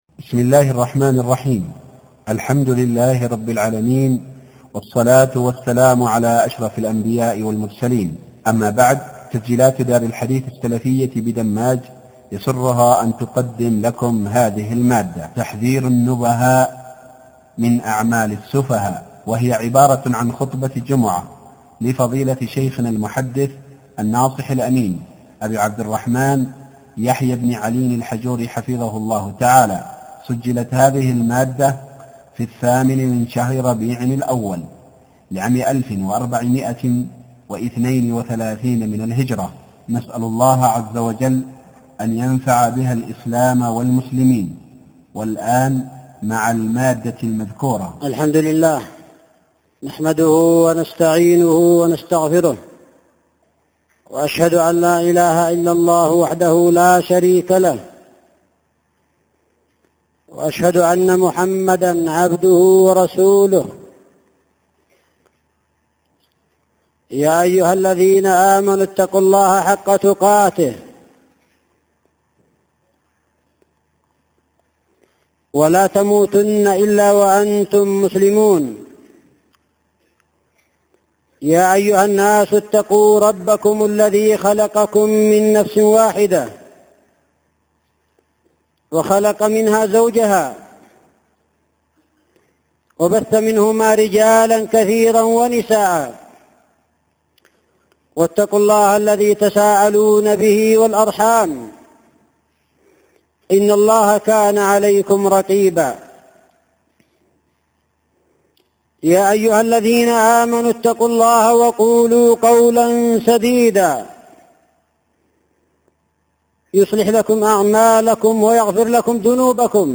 عنوان خطبة اليوم الجمعة 8 ربيع أول 1432هـ تحذير النبهاء من أعمال السفهاء